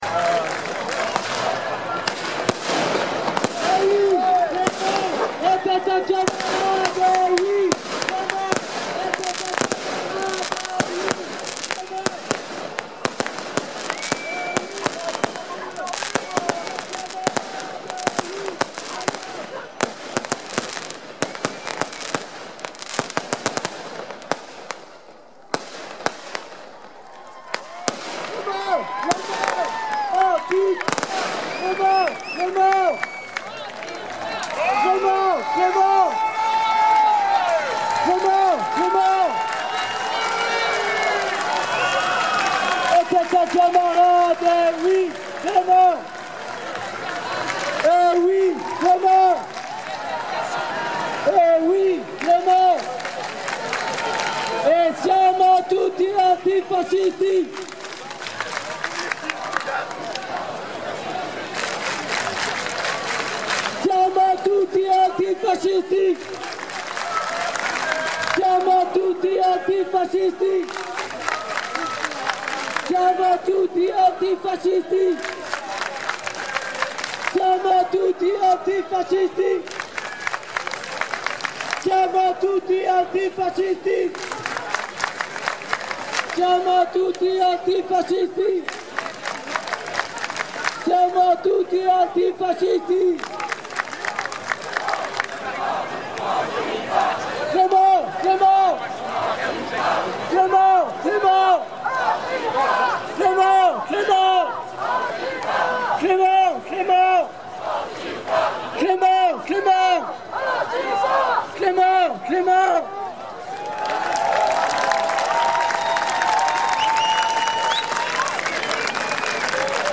Hommage à Clément Méric sur un pont des Rives de l'Orne
à 1 minute le reste de la manif passe et on entend chanter ça va péter